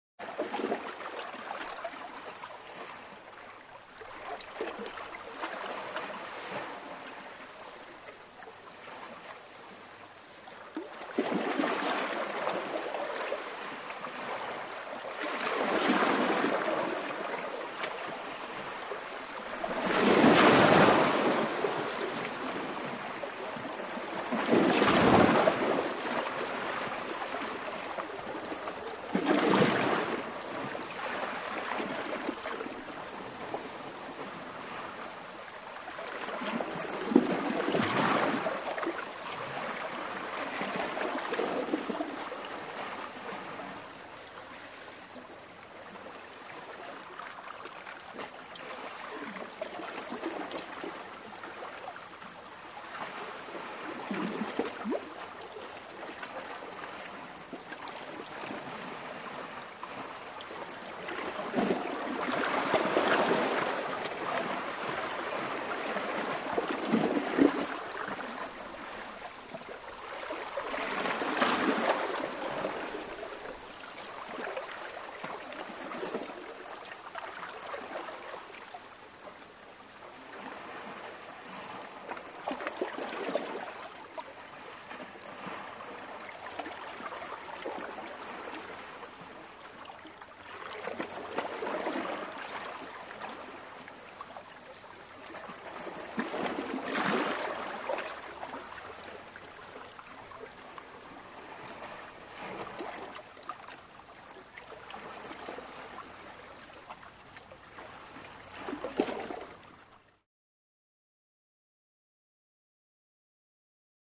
Water Laps 2; Lake Water Laps By A Dock With Some Surging Waves.